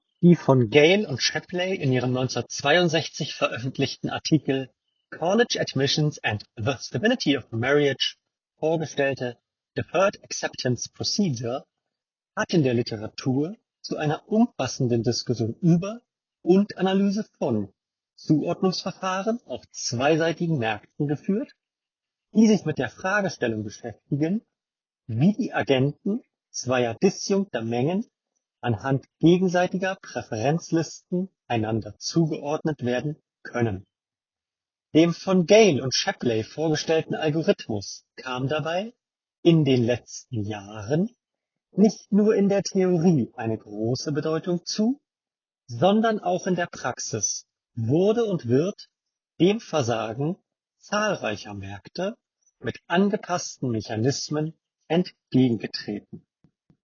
Bessere Telefonie
Die Umgebung wird stark gefiltert und dringt kaum ans andere Ende zum Hörer durch. Darunter leidet mitunter die Sprachqualität des Sprechers, da die Stimme in ihrem Klang und der Lautstärke je nach Hintergrundgeräuschen etwas variiert. Der Träger ist bei Anrufen dafür jedoch auch in lauterer Umgebung gut zu verstehen.
Sony WF-1000XM6 – Mikrofonqualität